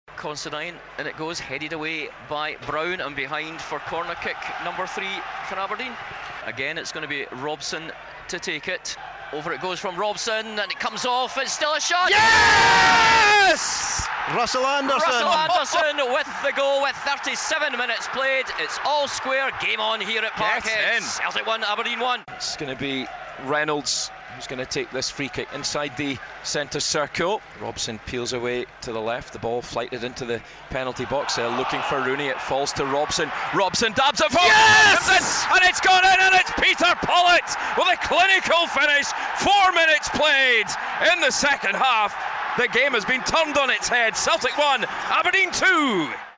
Celtic 1-2 Aberdeen. Red TV commentary in association with Northsound 1.